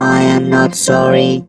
rick_kill_vo_01.wav